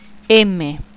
I have pronounced each letter for you. Click each button below to hear the sound of that letter.